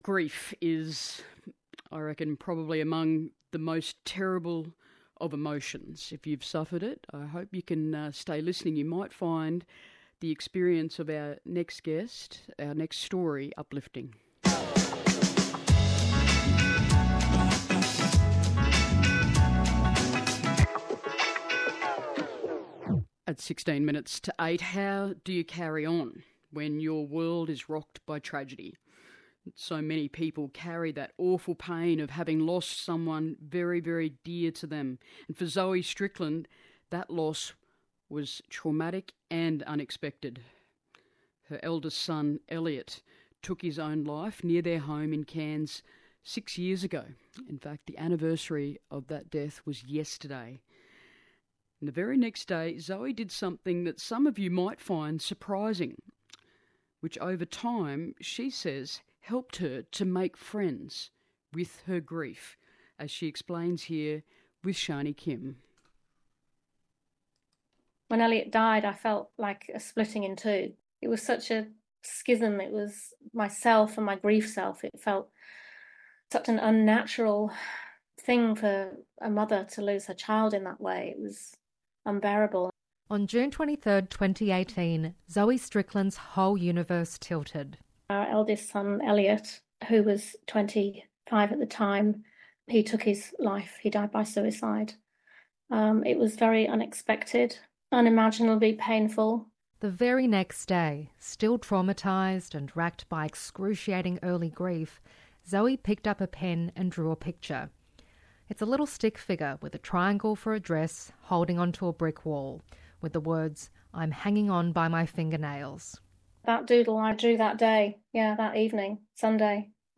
ABC Radio Interview